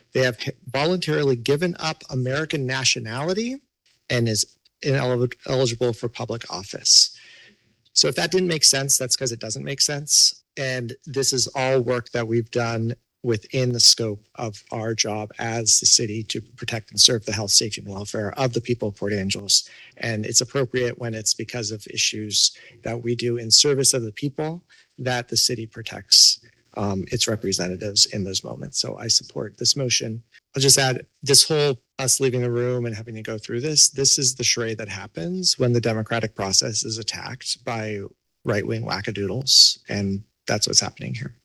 Councilor Schromen-Wawrin publicly read a portion of the complaint, defended the move to have any attorney’s fees paid by the City and added these comments.